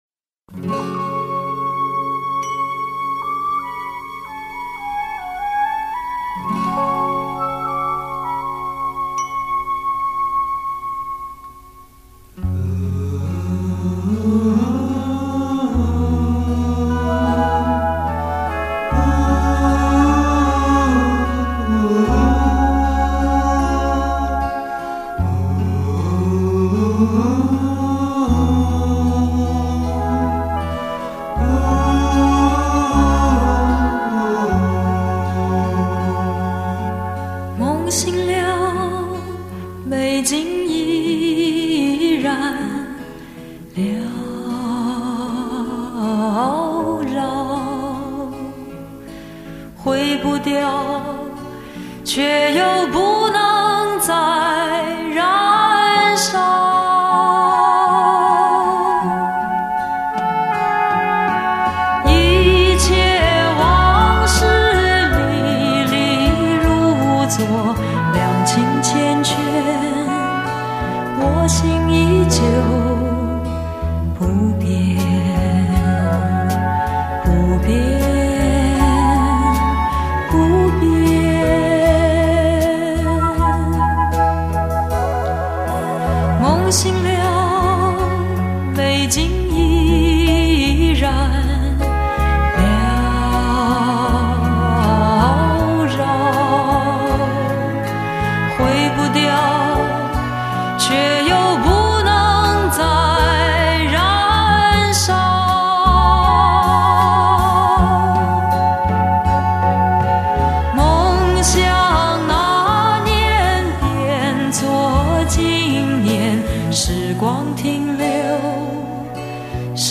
各种风格的完美演绎，甘醇、温婉、忧伤、温暖、或激昂铿锵，堪称不退流行的经典吟唱。